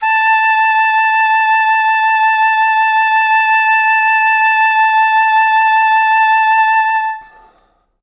Index of /90_sSampleCDs/Best Service ProSamples vol.51 - Classic Orchestra 2 [AIFF, EXS24, HALion, WAV] 1CD/PS-51 AIFF AOE/Oboe